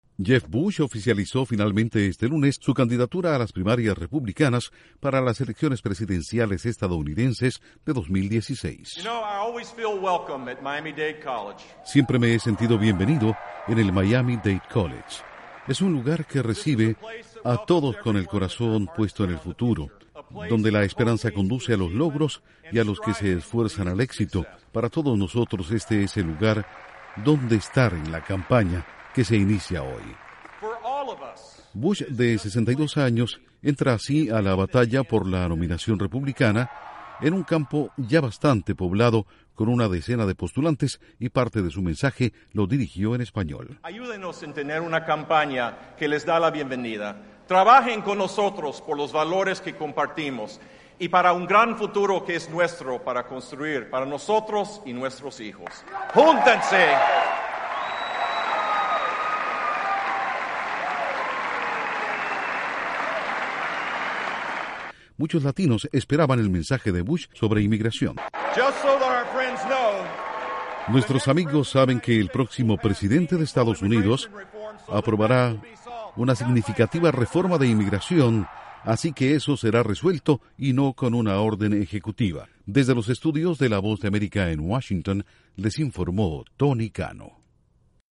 Jeb Bush oficializa su candidatura a las primarias republicanas para las elecciones presidenciales de 2016. Informa desde los estudios de la Voz de América en Washington